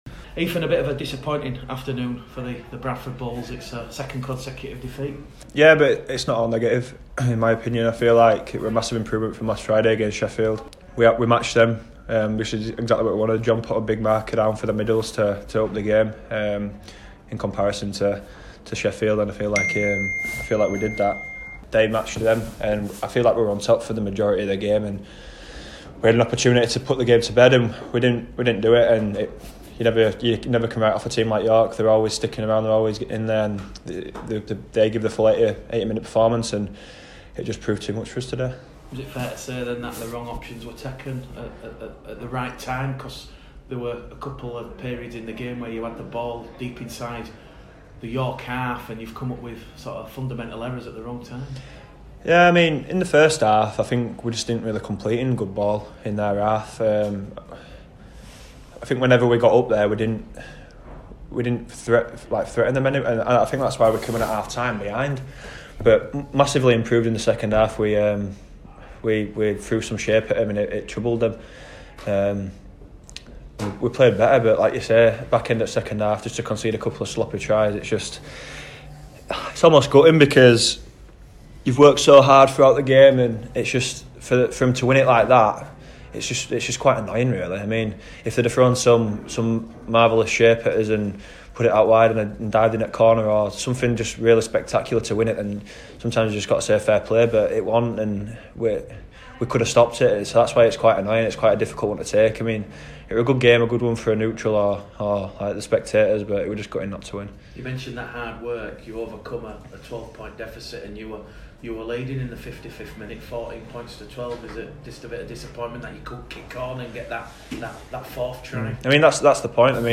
Post-York Interview